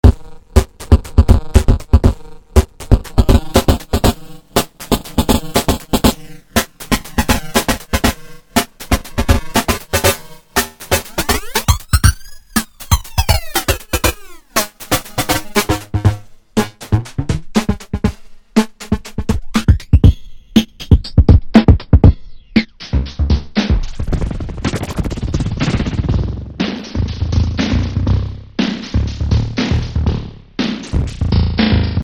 Another example of some more esoteric settings.